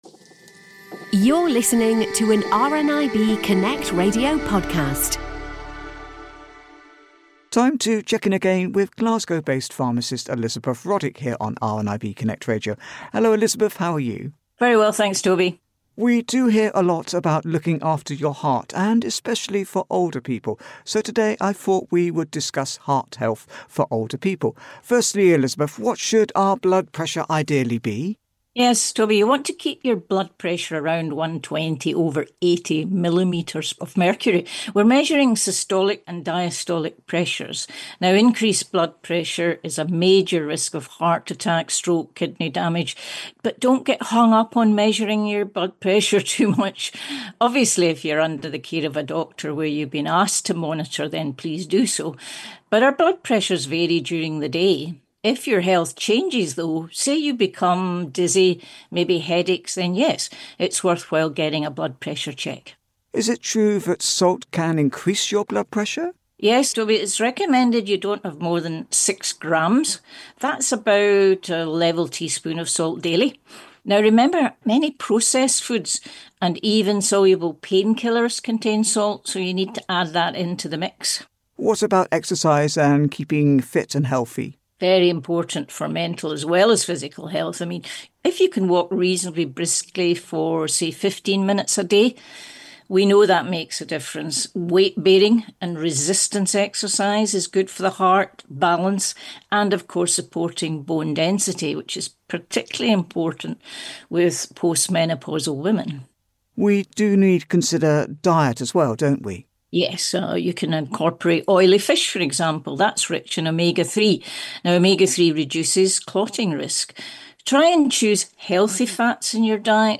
Then to discussing about exercise, diet and how being healthy is also good for your heart too.